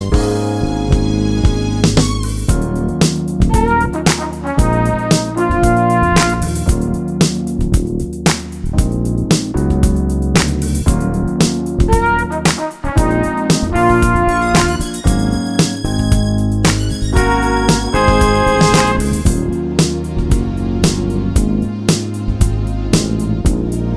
No Guitars Or Backing Vocals Pop (1980s) 3:43 Buy £1.50